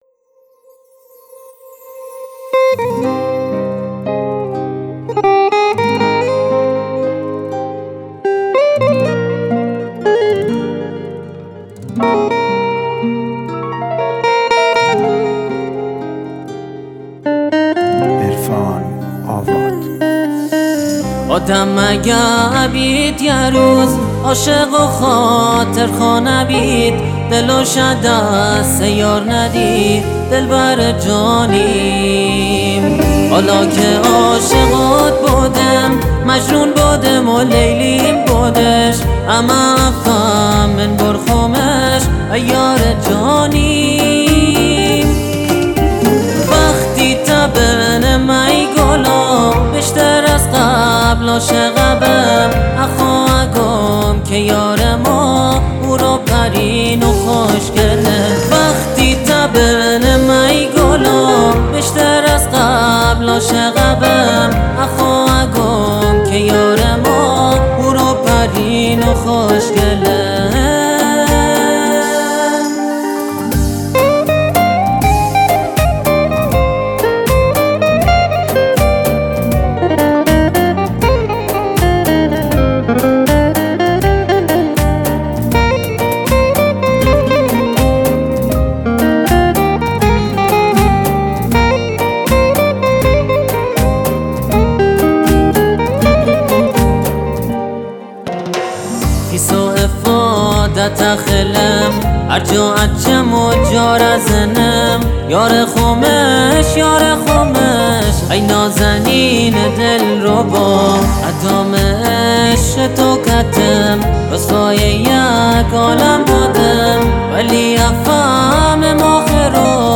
بستکی